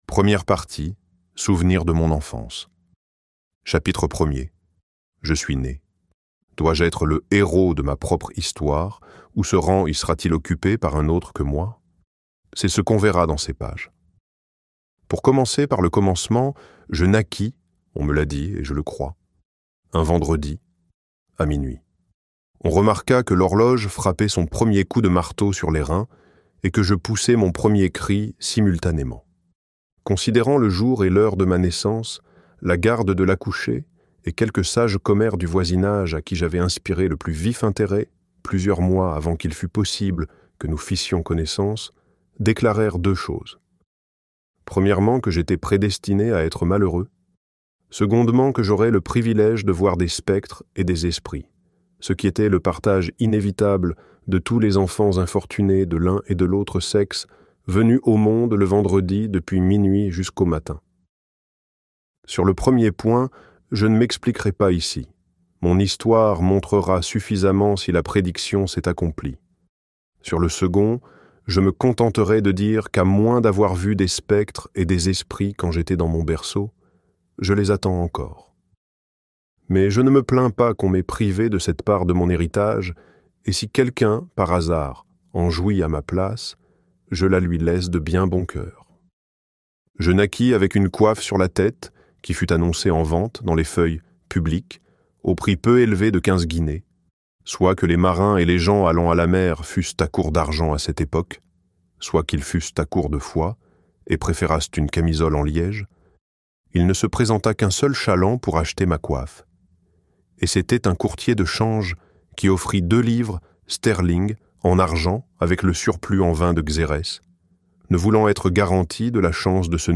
David Copperfield - Livre Audio